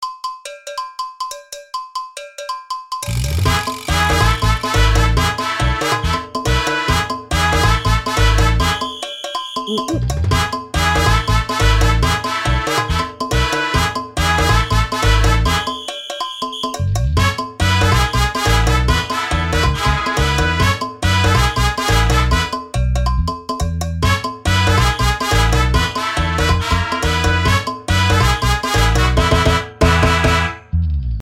なんかこういうアホな曲って、作ってて楽しいんよね
Jingle.mp3